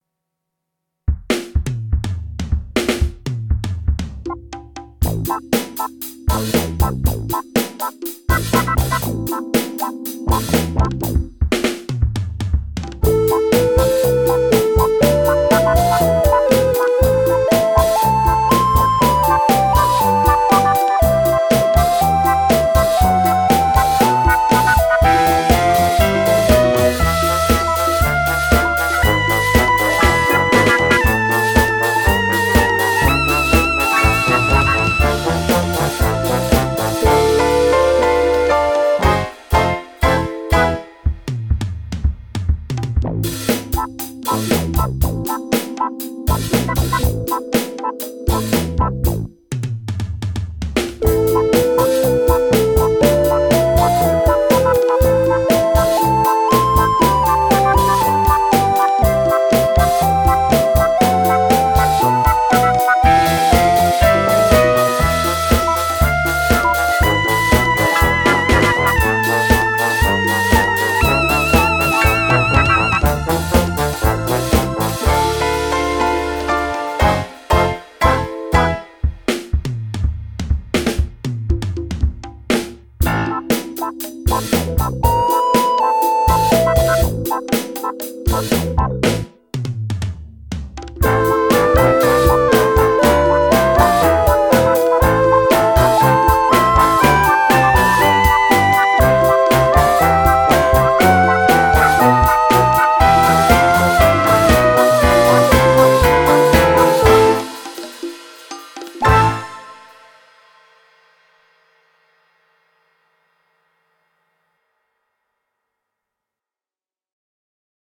very short and to the point funky disco interpretation